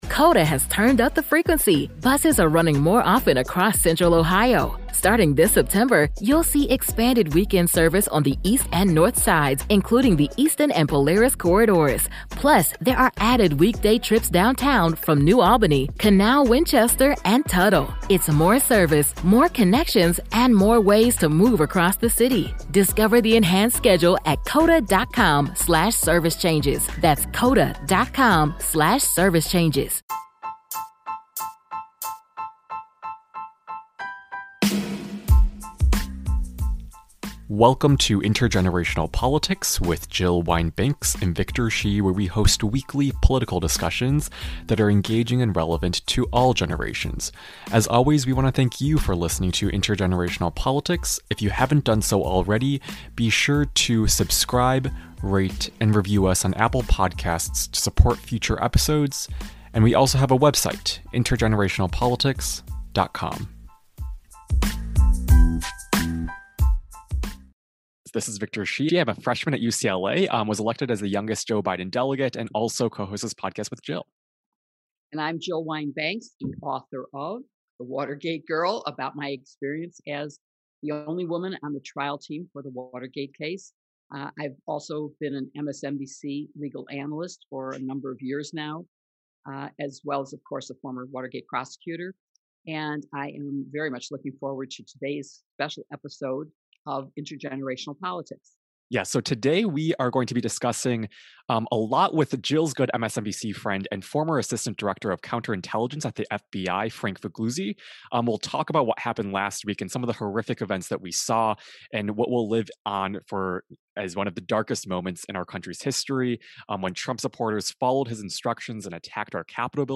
In this important episode, Frank Figliuzzi, former, MSNBC National Security Contributor and former Assistant Director of counterintelligence at the FBI, joins us to discuss the Capitol's insurrection on January 6th, how it happened, what is being done by the FBI now to figure ...